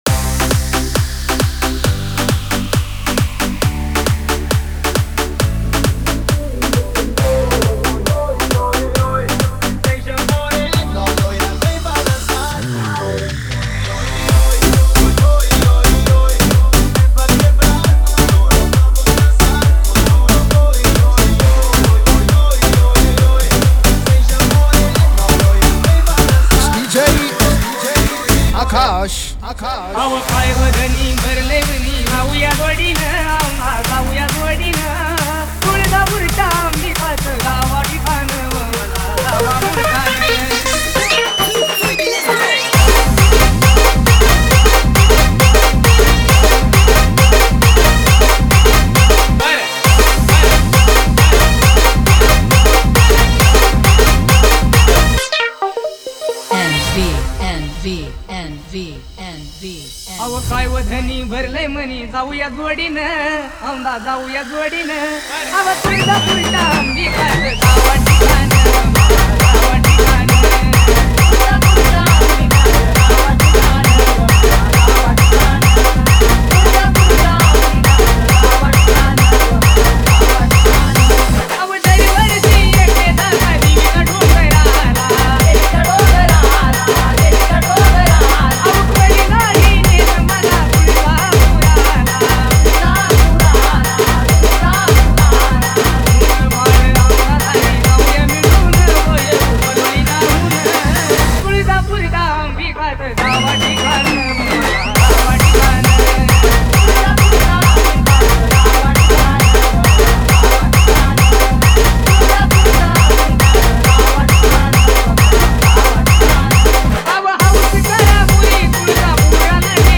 Navratri Dj Remix Song Play Pause Vol + Vol -